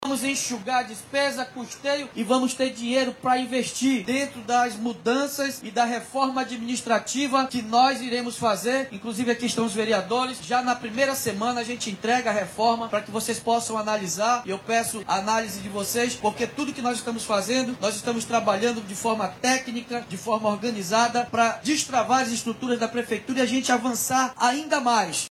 Durante a entrega de novos ônibus, na manhã desta sexta-feira 03/01, o Prefeito de Manaus, Davi Almeida, anunciou o aumento da tarifa do Transporte Coletivo, para este ano.